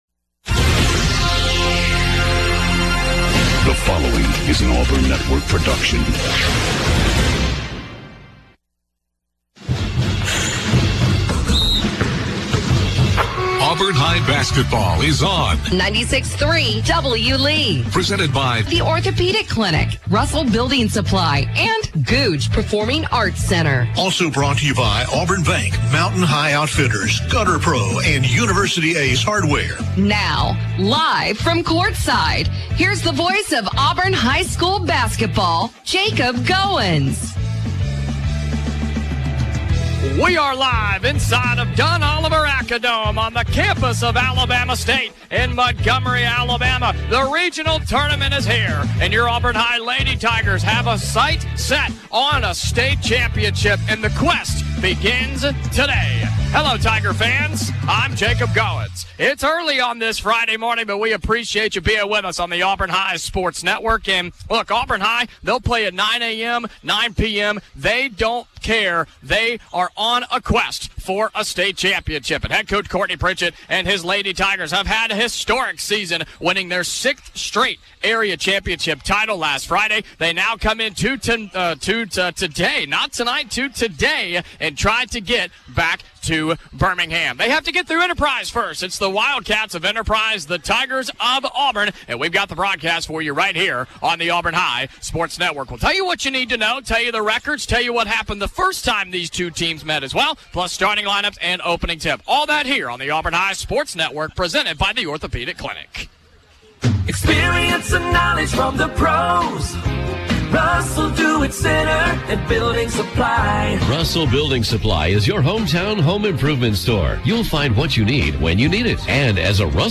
as he calls Auburn High's game versus Enterprise in the State Tournament Sweet Sixteen. The Tigers won 74-18.